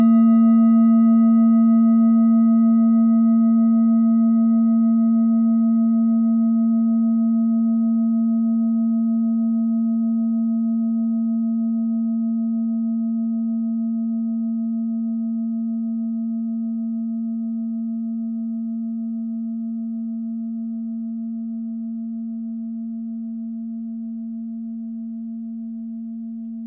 Klangschalen-Typ: Bengalen
Klangschale Nr.3
Gewicht = 790g
Durchmesser = 16,9cm
(Aufgenommen mit dem Filzklöppel/Gummischlegel)
klangschale-set-1-3.wav